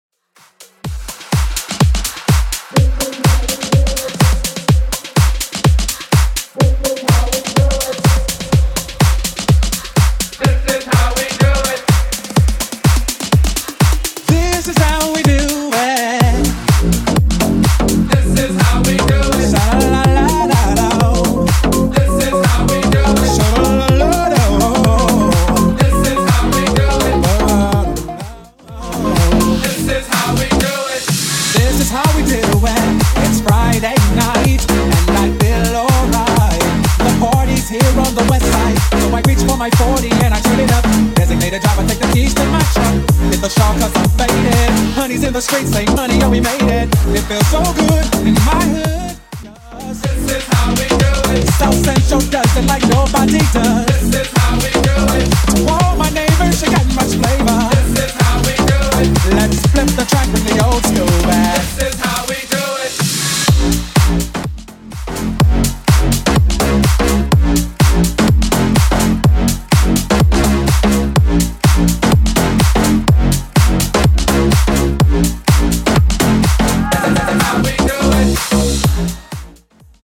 BOOTLEG , TOP40 124 Clean